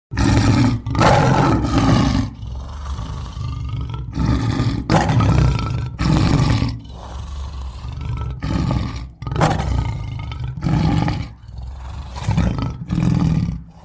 Ringetone Løve r
Kategori Dyr
love-r.mp3